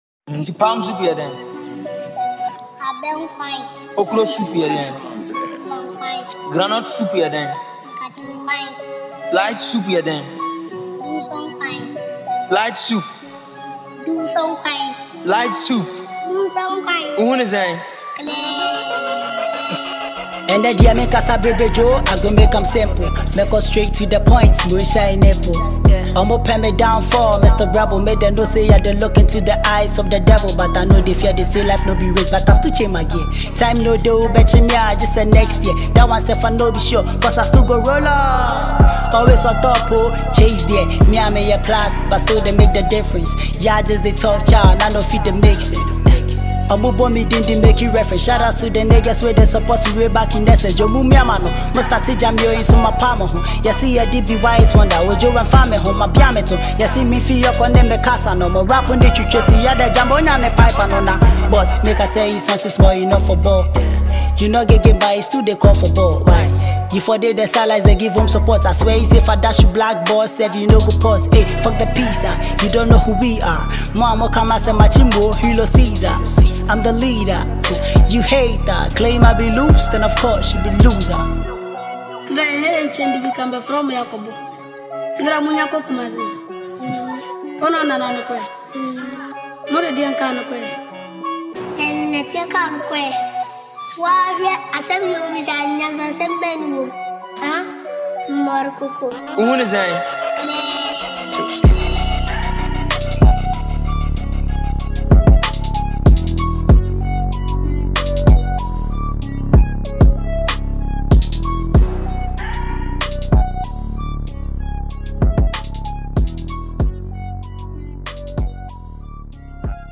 Ghanaian rapper
hardcore trap tune